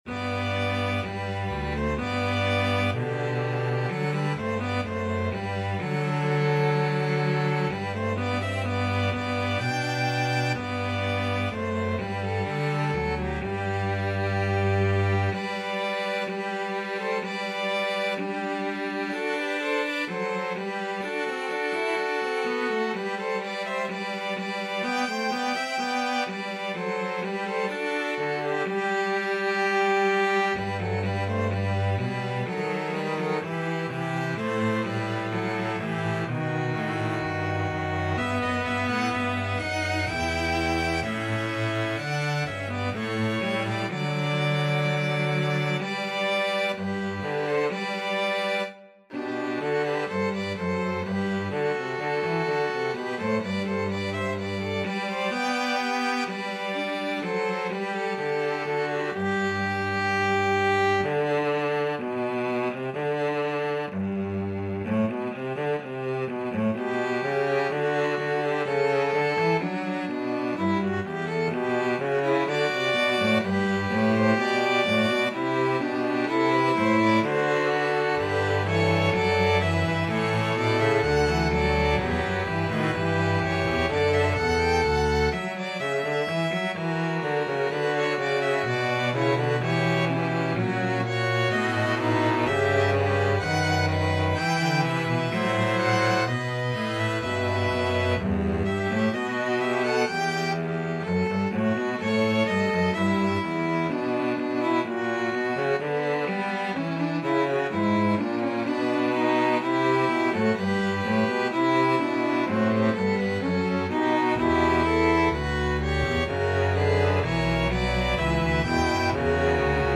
(2vn, vc, db)
Violin 1Violin 2CelloDouble Bass
4/4 (View more 4/4 Music)
Moderato (=63)
Classical (View more Classical String Ensemble Music)